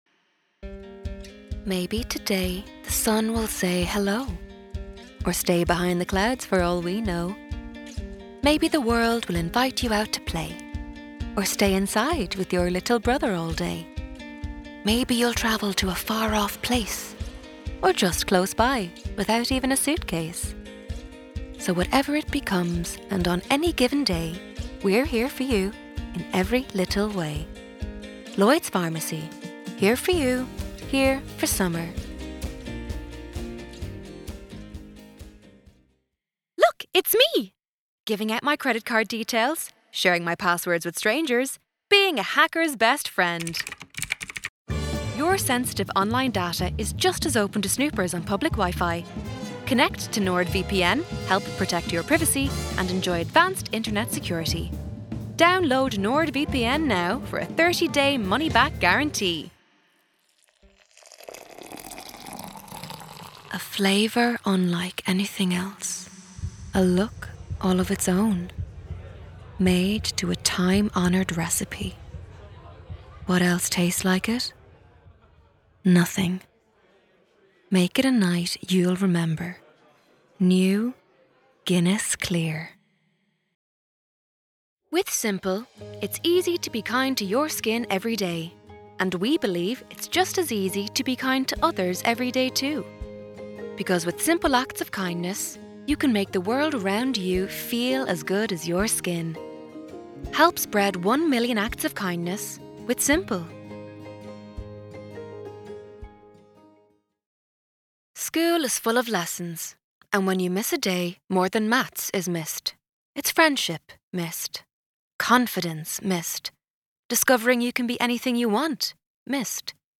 Voice samples
Commercial & Narration Reel